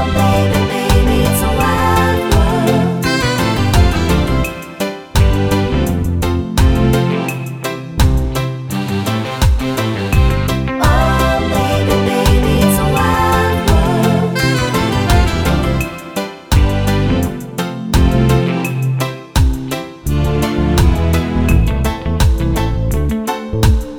no sax Reggae 3:31 Buy £1.50